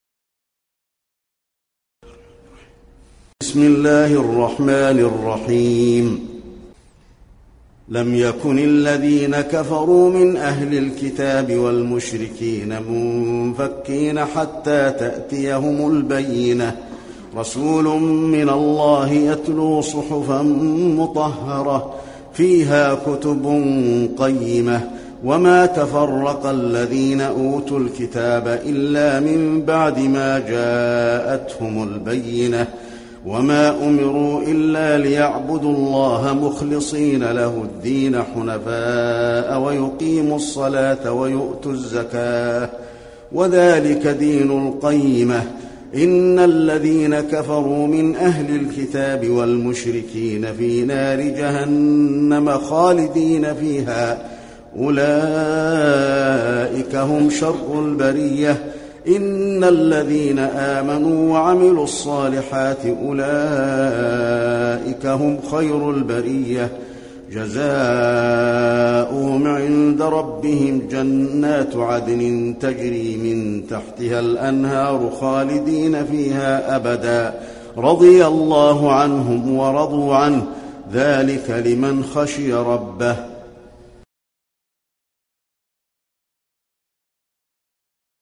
المكان: المسجد النبوي البينة